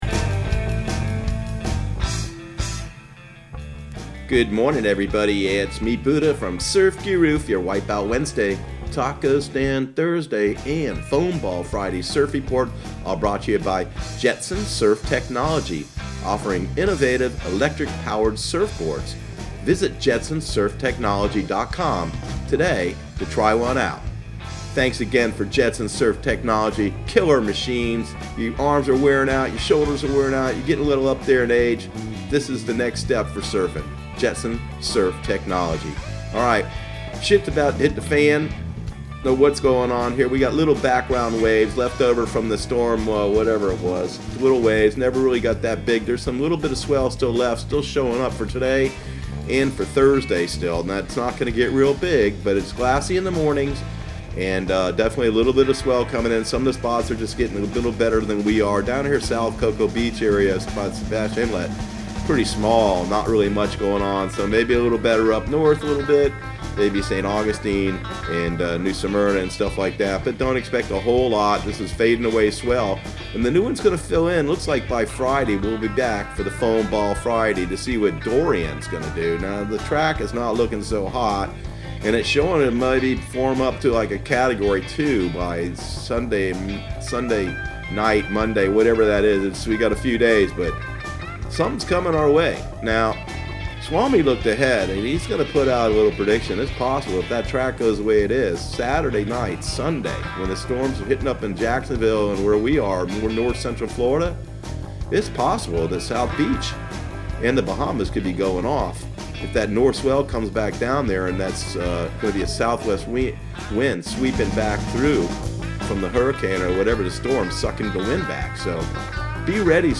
Surf Guru Surf Report and Forecast 08/28/2019 Audio surf report and surf forecast on August 28 for Central Florida and the Southeast.